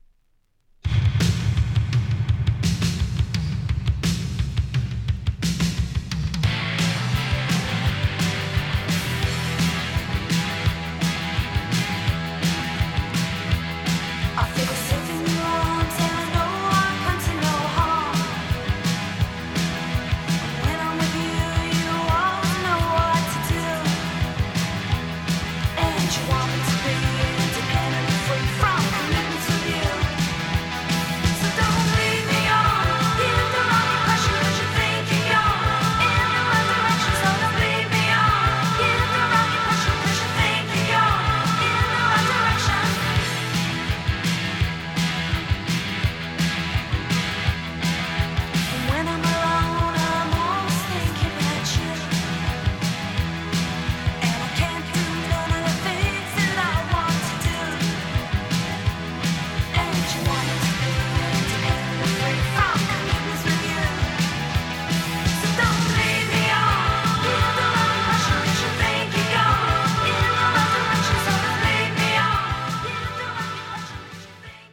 80's female post punk!!